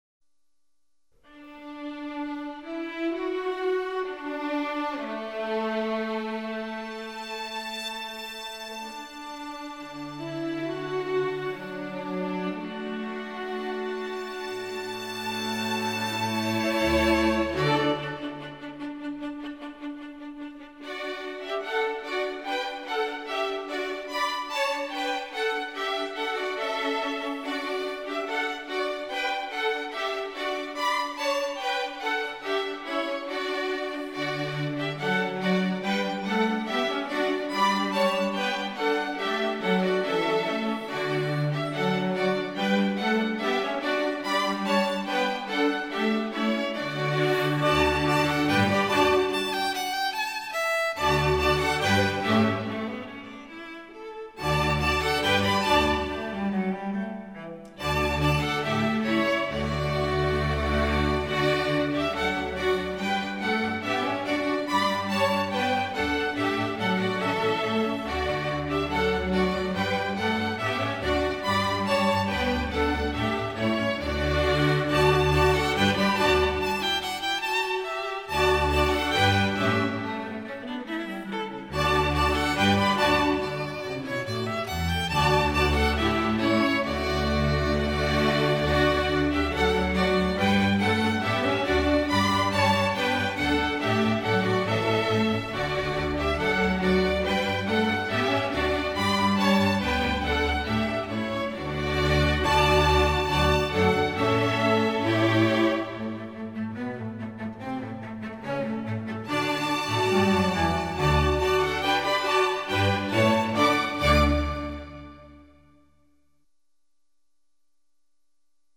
Voicing: String Orc